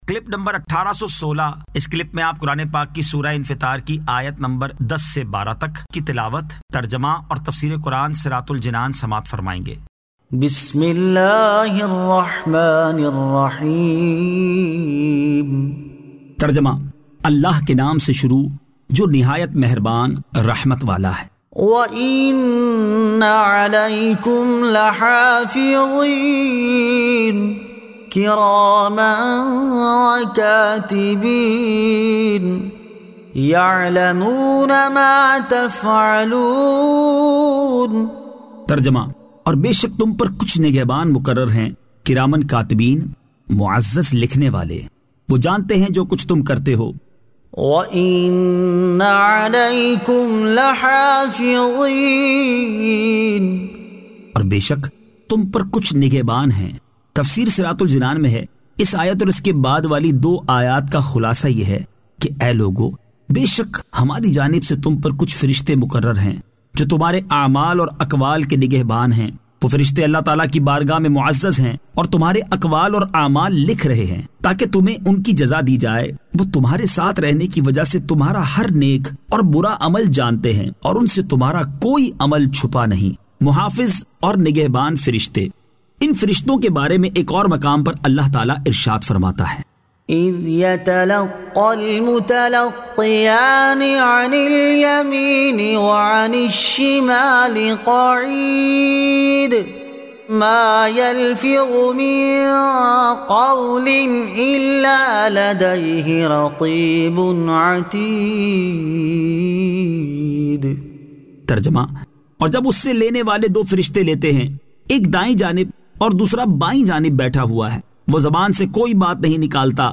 Surah Al-Infitar 10 To 12 Tilawat , Tarjama , Tafseer